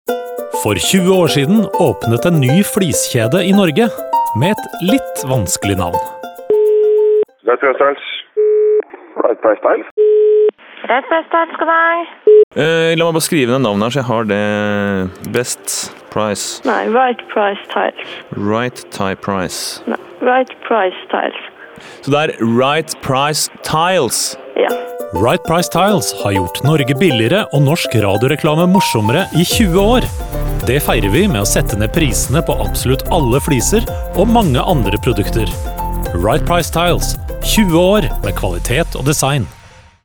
Telefonsamtaler er et grep som er brukt en del i radioreklame, som også gjør jobben enda mer krevende for neste annonsør ut.